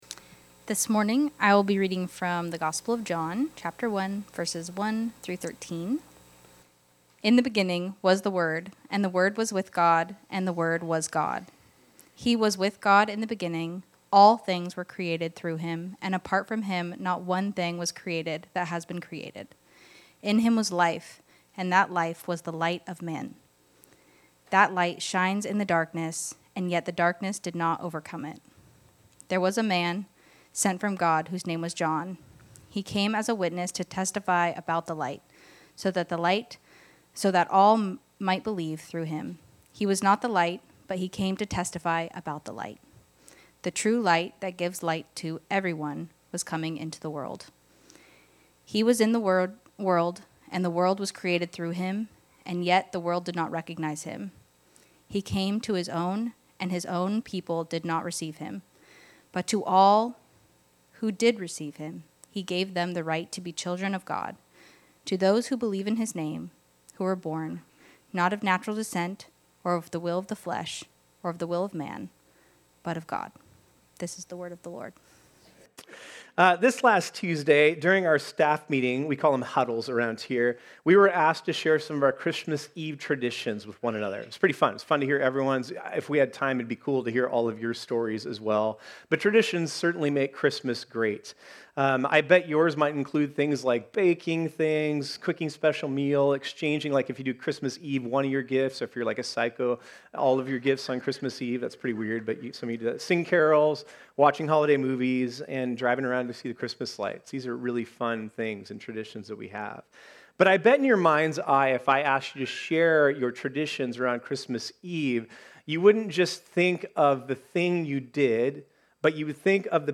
This sermon was originally preached on Sunday, December 21, 2025.